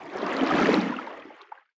Sfx_creature_seamonkey_swim_slow_02.ogg